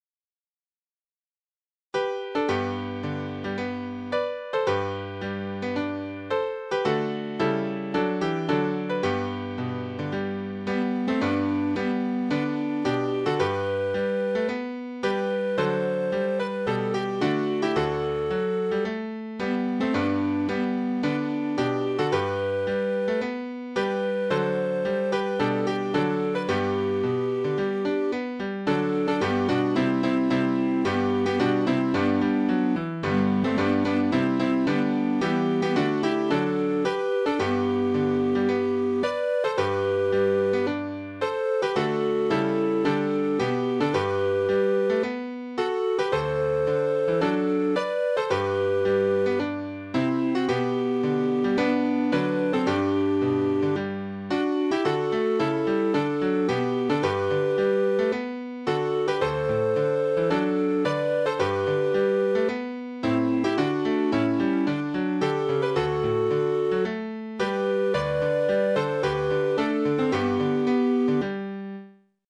校歌 - 矢板市立泉中学校